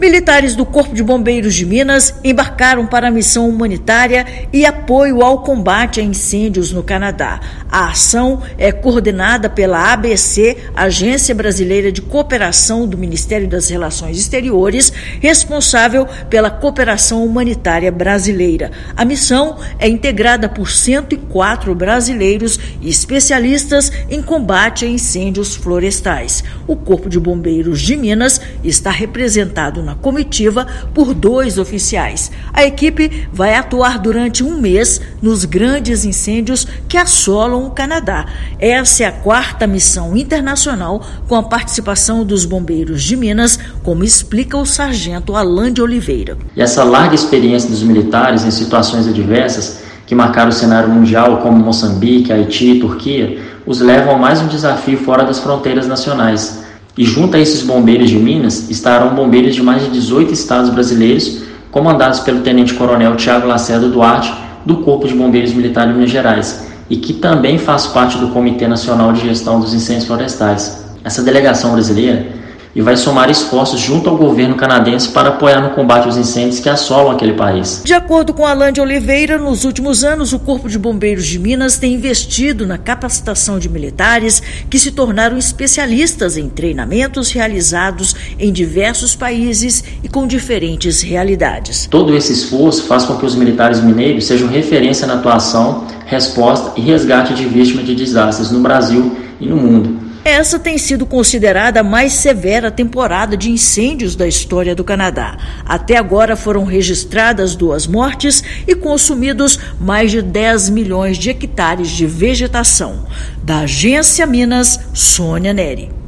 Grupo brasileiro vai prestar apoio no combate aos incêndios florestais que atingem o país desde junho. Ouça matéria de rádio.